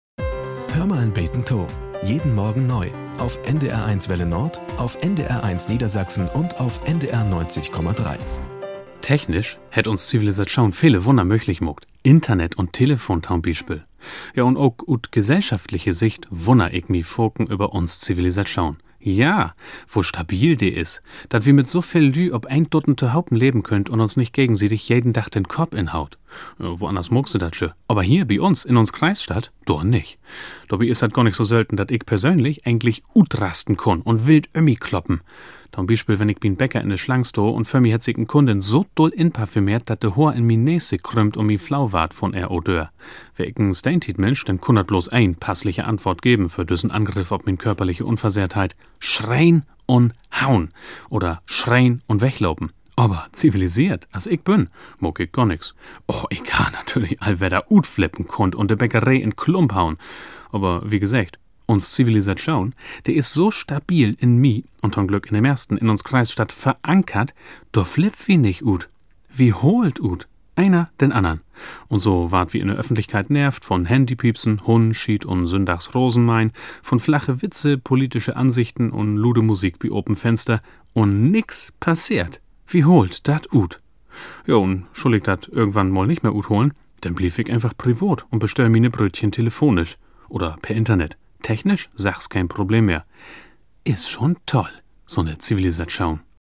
Nachrichten - 23.04.2023